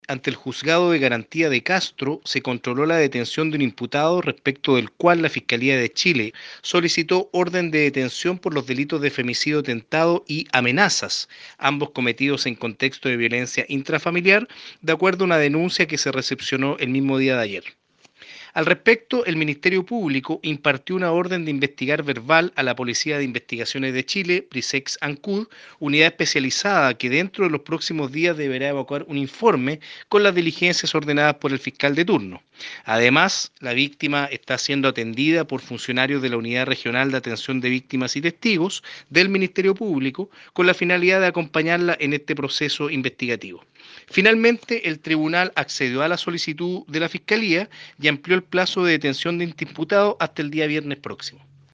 El fiscal (S) Cristian Mena, de la fiscalía de Castro, destacó que ante la gravedad de los hechos y a la espera del informe de la brigada especializada de la PDI, es que se pidió la ampliación de la detención hasta el viernes 03 de marzo.